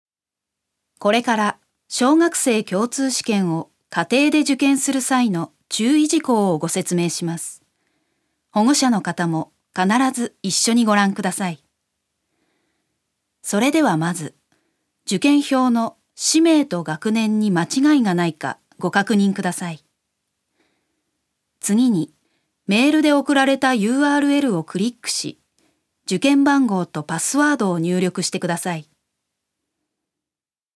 女性タレント
ナレーション２